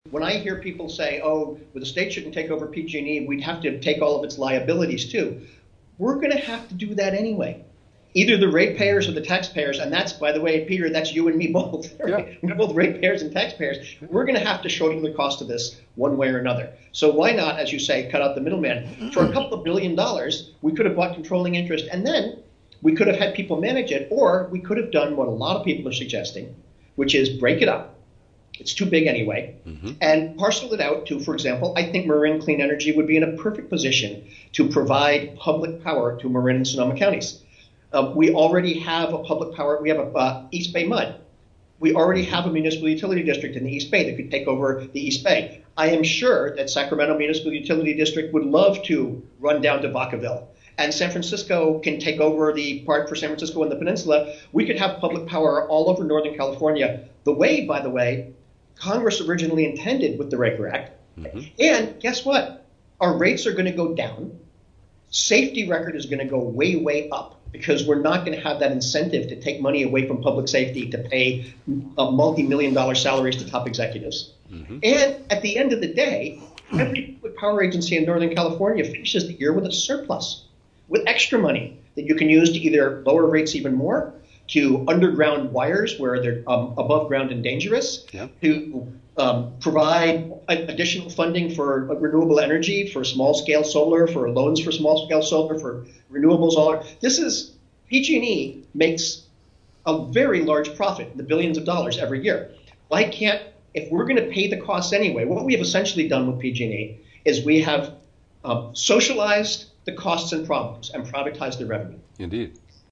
In-Depth Interview: A Discussion of Public Ownership of Pacific Gas & Electric